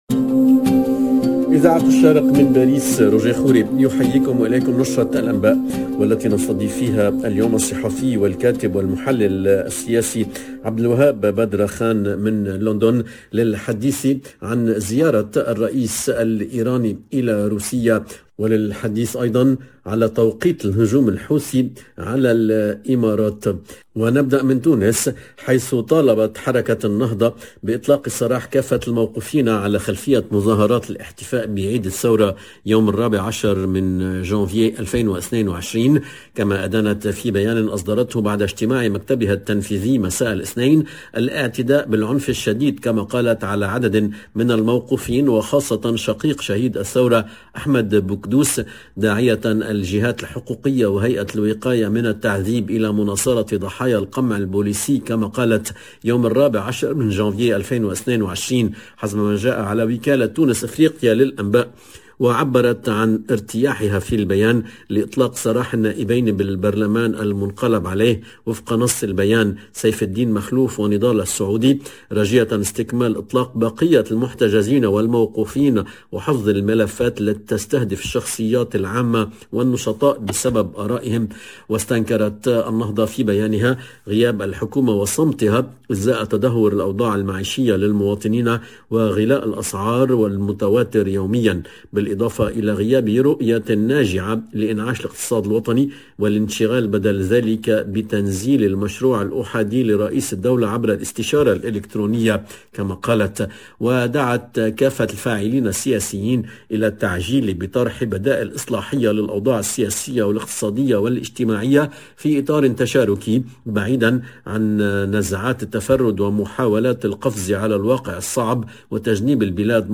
LE JOURNAL EN ARABE DE MIDI DU 19/01/22